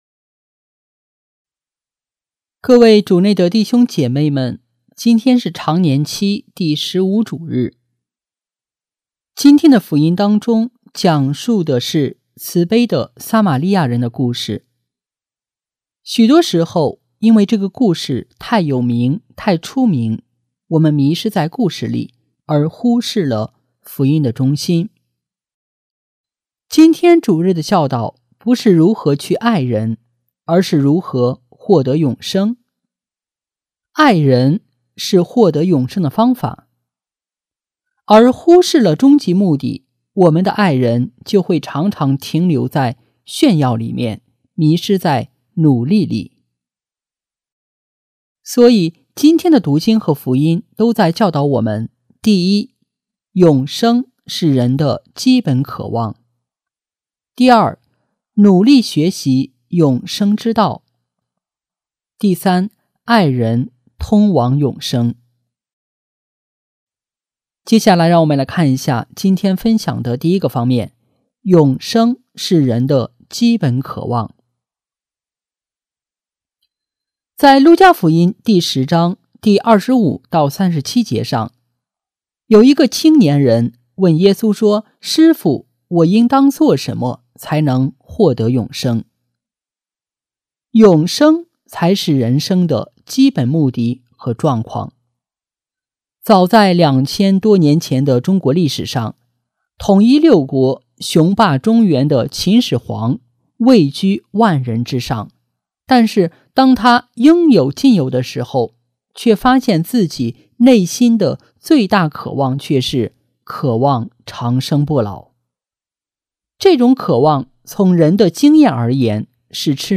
【主日证道】| 永生才是人生 （丙-常年期第15主日）